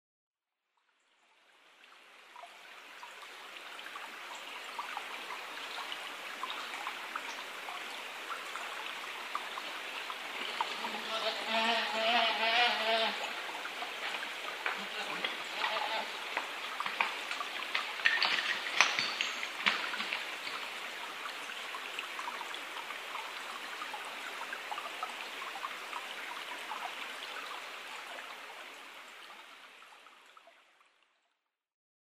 不明種　unknown
日光市土呂部　alt=940m
Mic: Panasonic WM-61A  Binaural Souce with Dummy Head
「メエェェエー」という声と岩が転がり落ちる音。